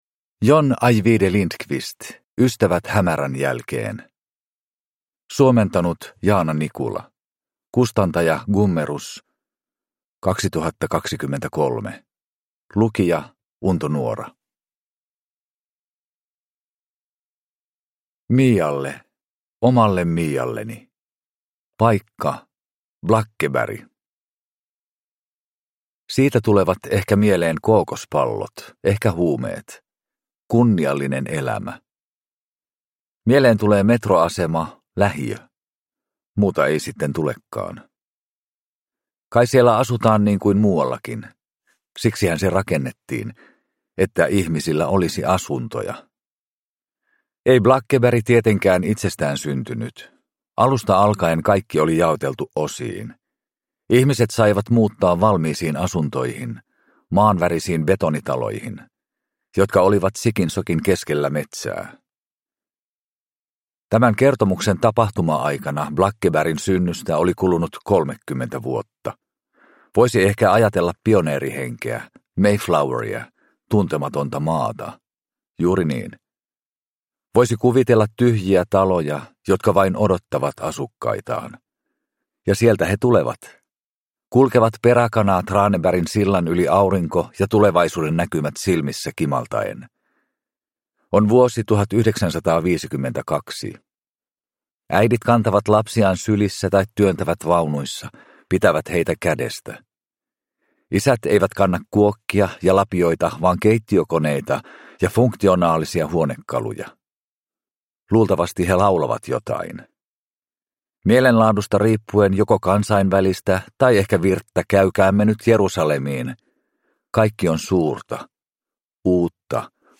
Ystävät hämärän jälkeen – Ljudbok